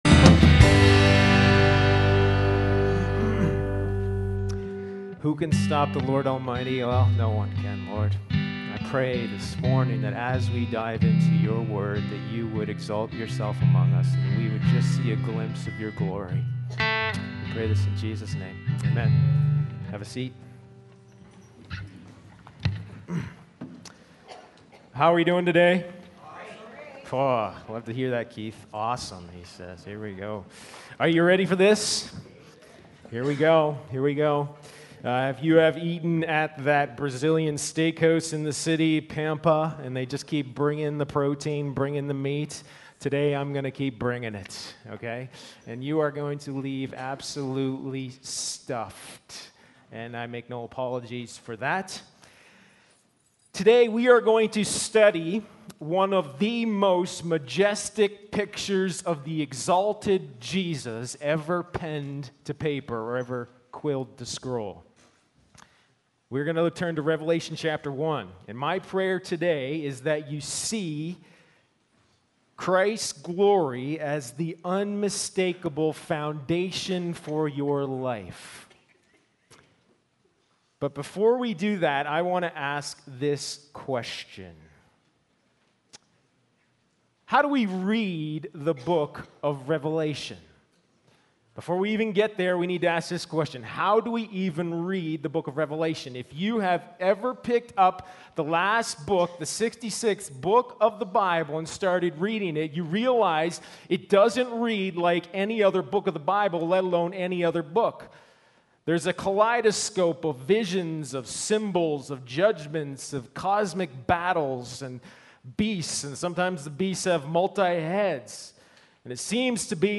Sermons | Sturgeon Alliance Church